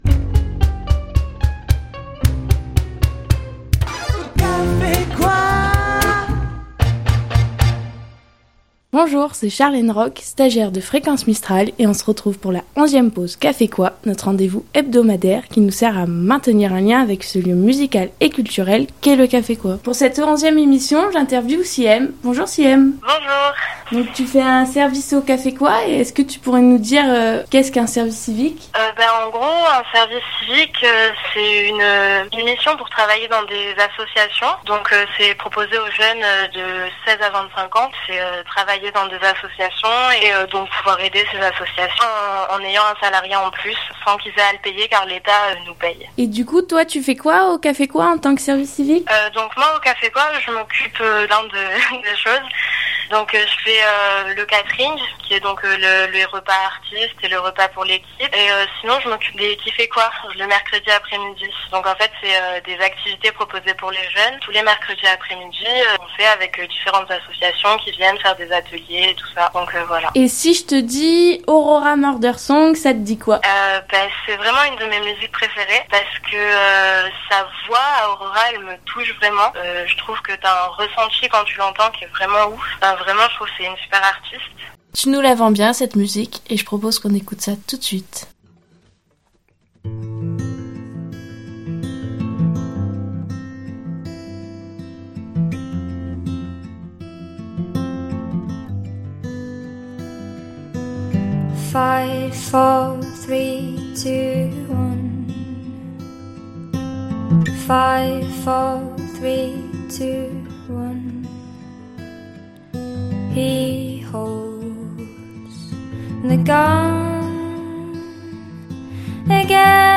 Pause K'fé Quoi 11.mp3 (14.68 Mo) Fréquence Mistral et le K'fé Quoi Forcalquier se retrouvent et partagent un onzième rendez-vous, sous forme d'une pause détente en musique avec un des membres de l'équipe qui vous confie ses goûts musicaux et que nous vous diffusons dans la foulée.